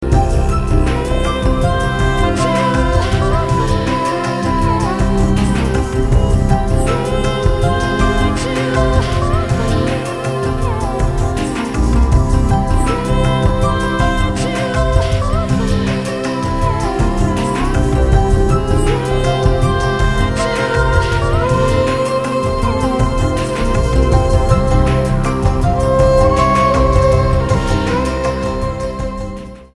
STYLE: World
Australian band
soulful and restful without being the tiniest bit boring.